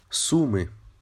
蘇梅烏克蘭語Суми羅馬化Sumy發音：[ˈsumɪ]
Uk-Суми.ogg.mp3